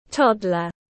Trẻ nhỏ mới biết đi tiếng anh gọi là toddler, phiên âm tiếng anh đọc là /ˈtɒd.lər/.
Toddler /ˈtɒd.lər/